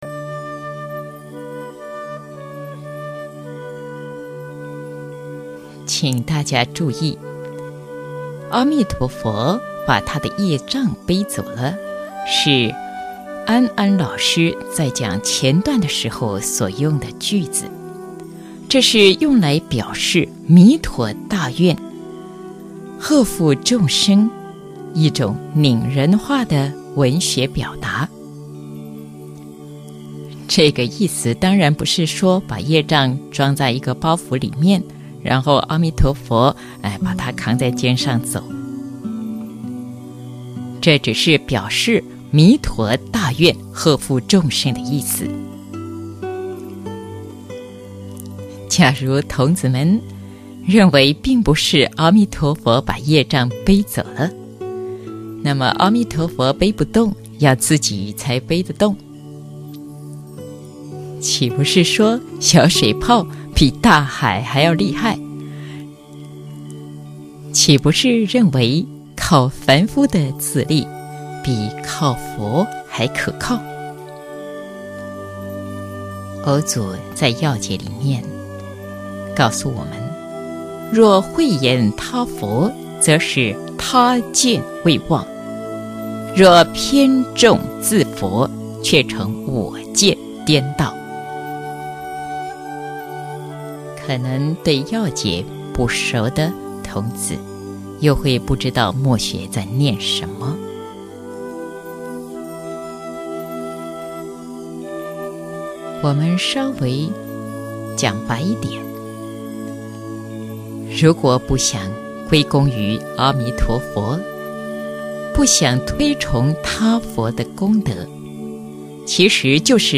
佛學講座 > 有聲書/廣播劇